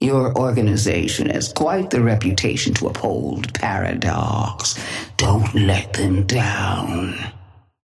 Sapphire Flame voice line - Your organization has quite the reputation to uphold, Paradox. Don't let them down.
Patron_female_ally_chrono_start_03.mp3